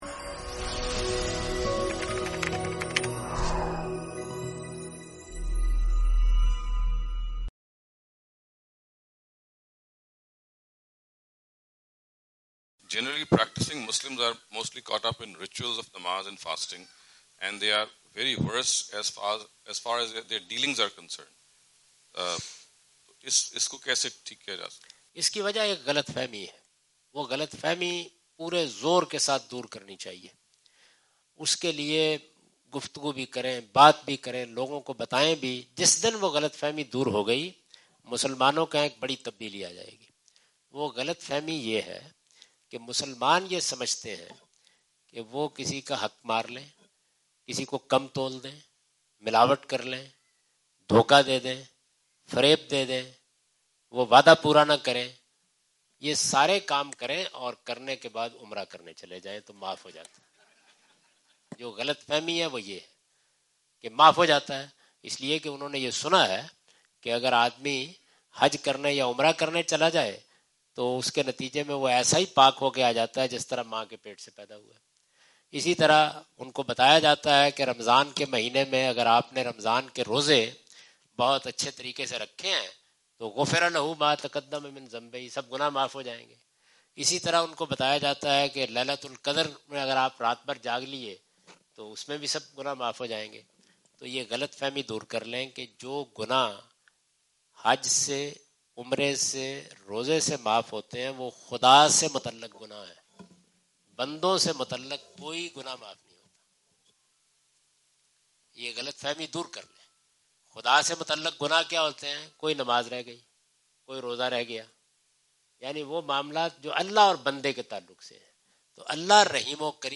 Category: English Subtitled / Questions_Answers /
Javed Ahmad Ghamidi answer the question about "Contradicting Attitude of Muslims in Religious and Worldly Matters" during his US visit on June 13, 2015.
جاوید احمد غامدی اپنے دورہ امریکہ 2015 کے دوران سانتا کلارا، کیلیفورنیا میں "مسلمانوں کا مذہبی اور دنیاوی معاملات میں متضاد رویہ" سے متعلق ایک سوال کا جواب دے رہے ہیں۔